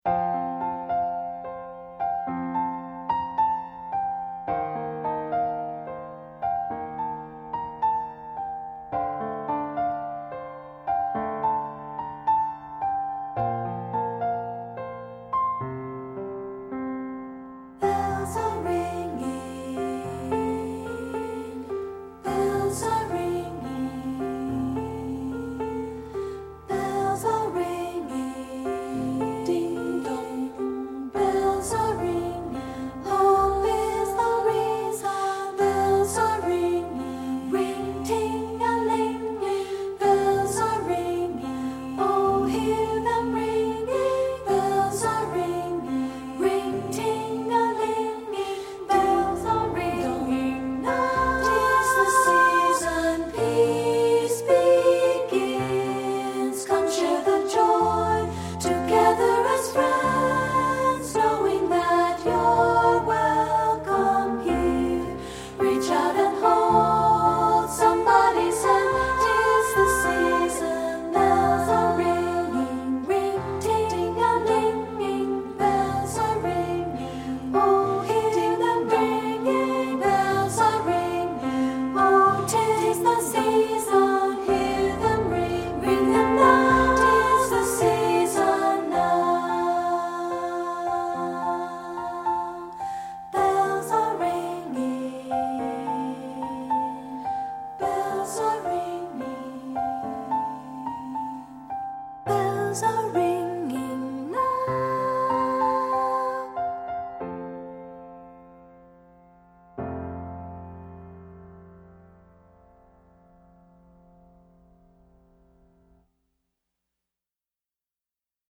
Studio Recording
with a peal-like quality to the voicing.
lends delicate treatment to treble voices
Ensemble: Unison and Two-Part Chorus
Key: F major
Accompanied: Accompanied Chorus